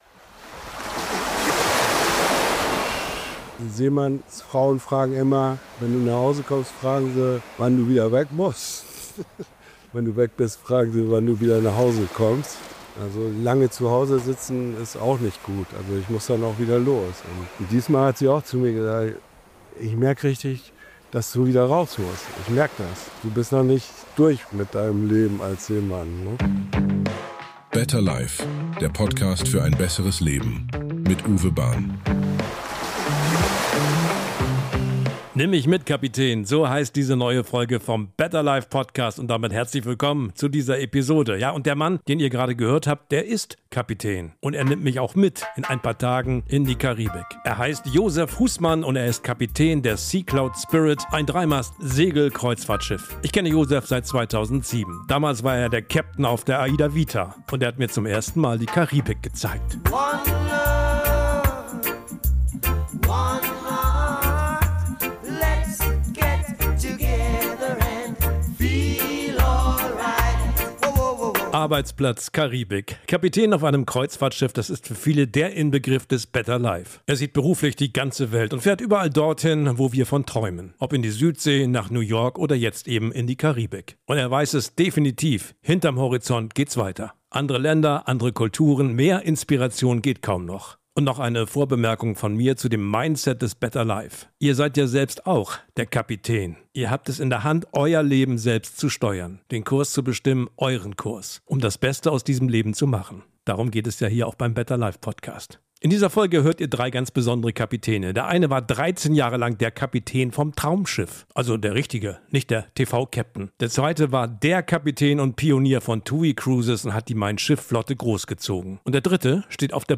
Drei Kapitäne erzählen aus ihrem Leben zwischen Traumschiff, AIDA und „Mein Schiff“.